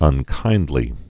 (ŭn-kīndlē)